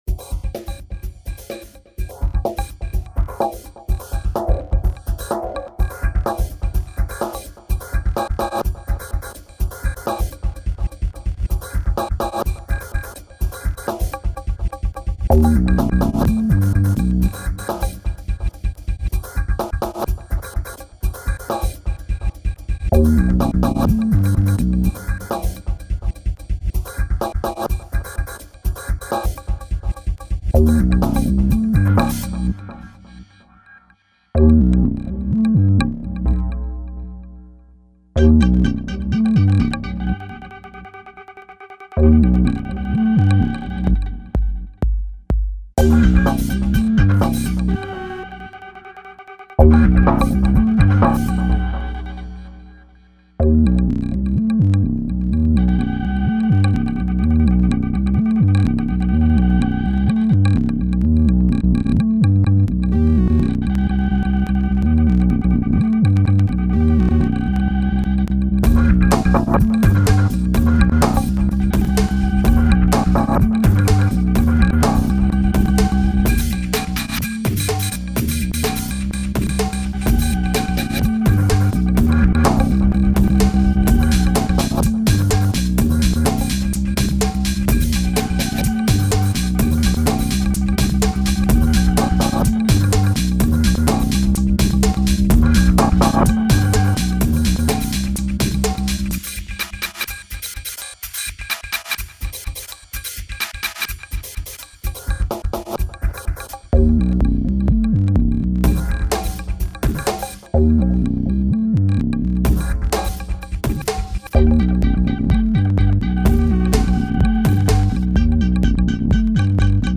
Created in Jeskola Buzz overnight.